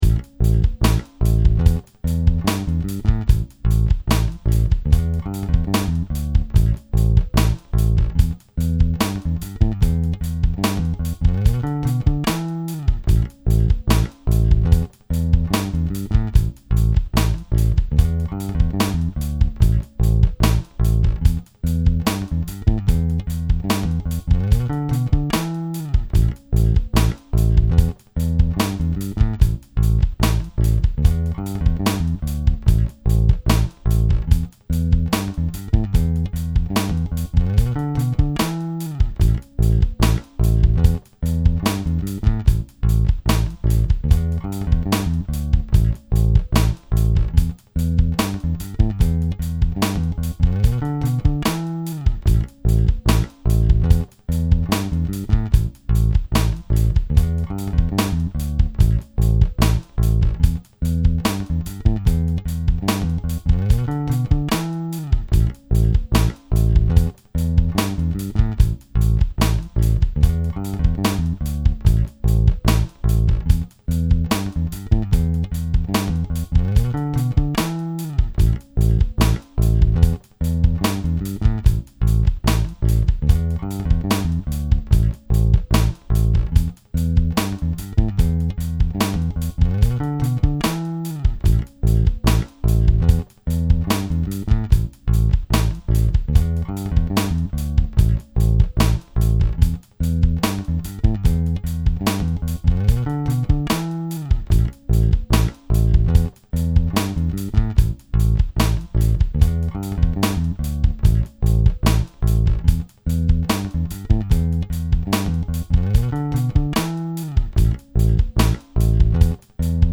147 Rhythm Section